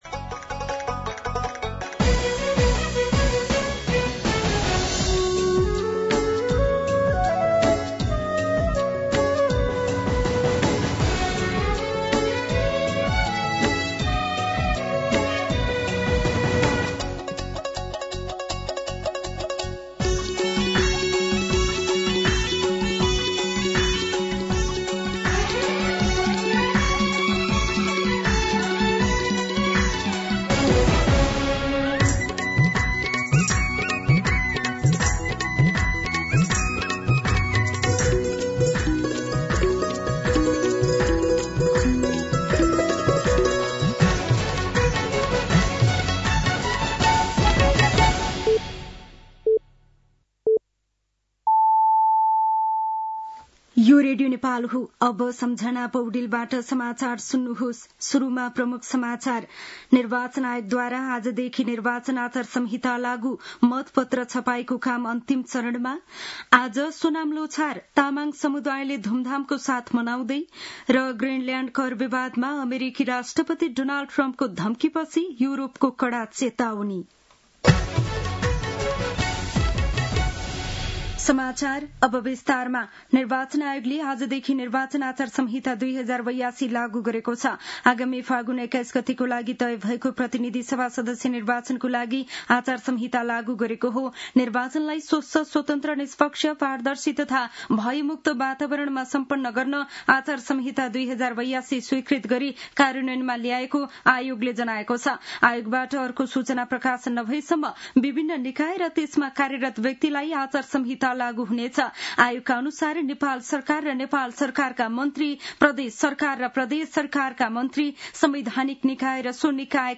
दिउँसो ३ बजेको नेपाली समाचार : ५ माघ , २०८२